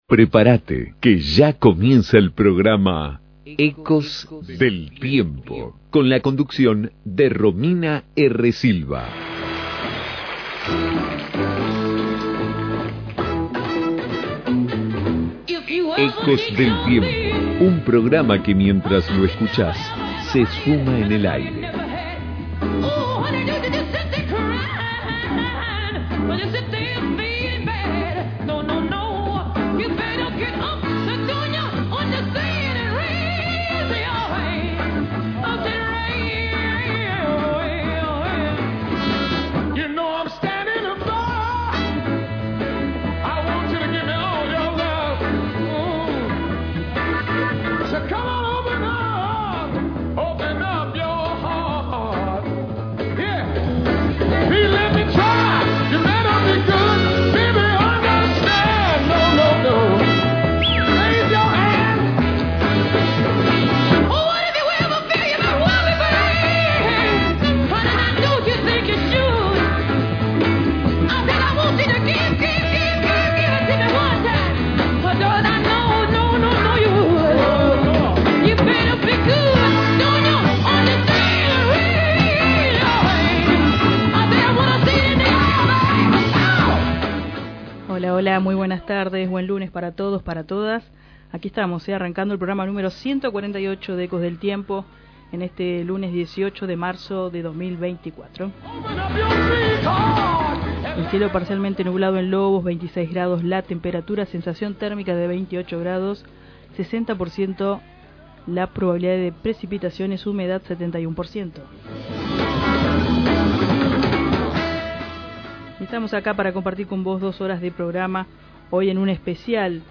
Noticias de actualidad 🎶🎶🎶 🎙🙂 Entrevista